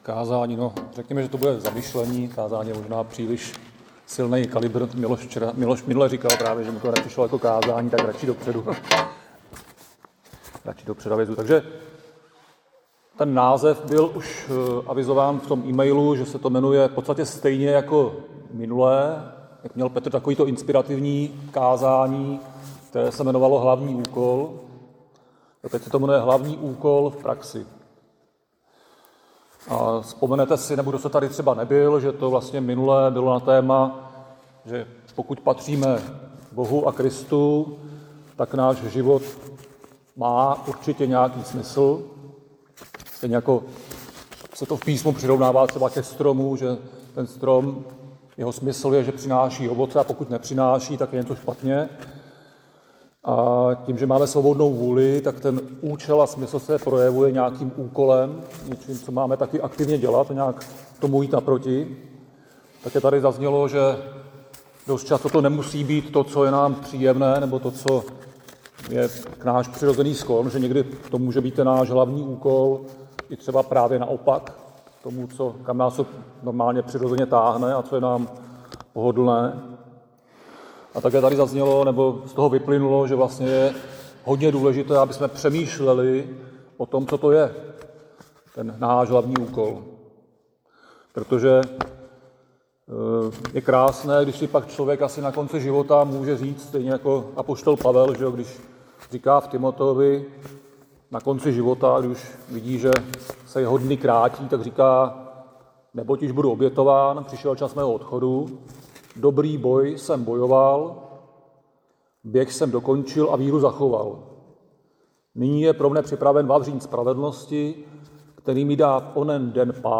Křesťanské společenství Jičín - Kázání 16.2.2025